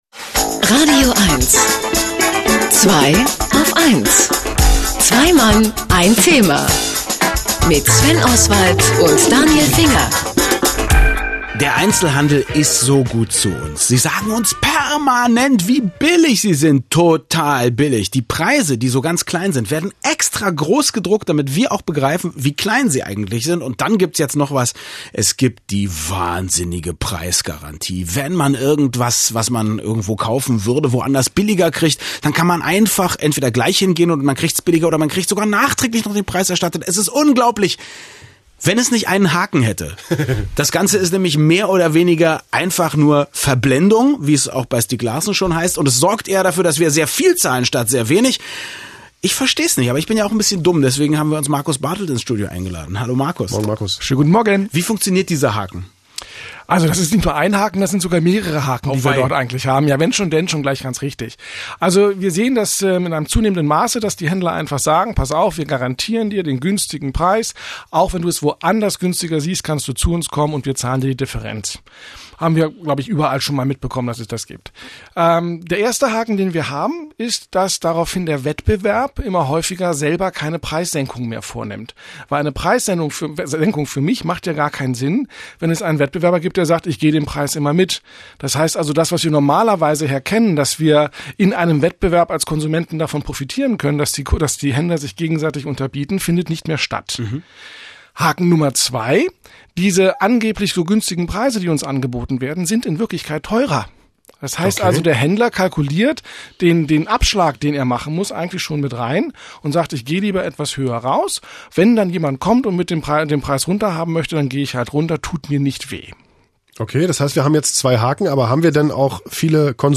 Irgendwie hat ja alles im Leben einen Haken und so wundert es nicht, dass ich bei den Herren von „2aufeins“ in das radioeins-Studio geladen war, um dort über den Haken bei den sogenannten Bestpreisangeboten zu sprechen: